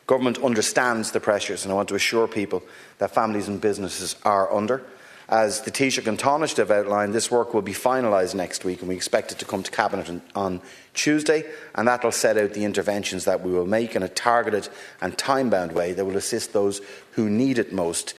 Energy Minister Darragh O’Brien says they will take the time to get it right: